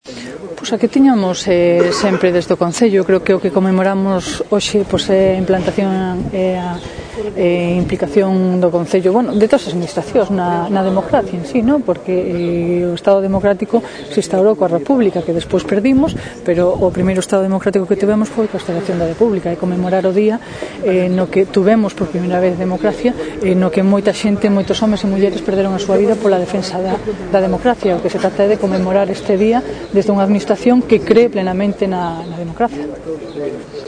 Lo hizo con una ofrenda floral y la lectura de un manifiesto en la Plaza de la Soledad, enfrente del monolito que rinde homenaje a la República, actos a los que asistieron diversos miembros de la Corporación Municipal, presidida por la Alcaldesa de Lugo, Lara Méndez, quien fue la encargada de dar voz al manifiesto conmemorativo.